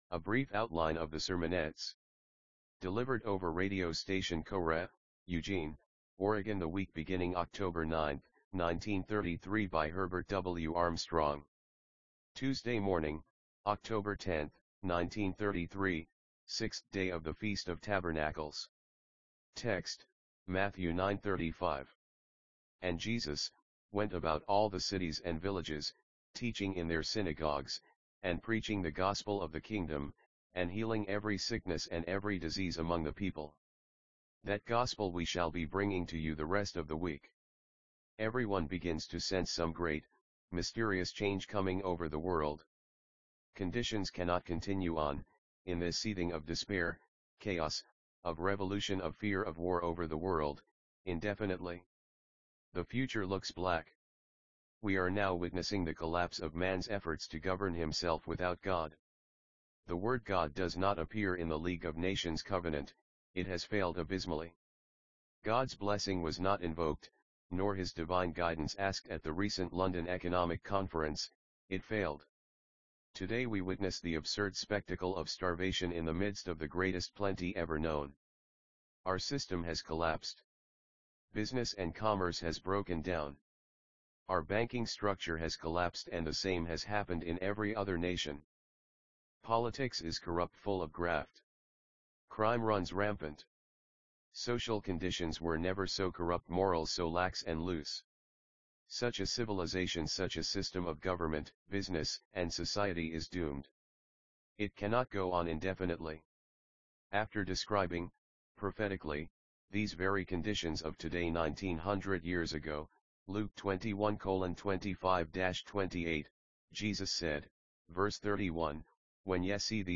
NB: a program was used to convert each of these broadcasts to MP3.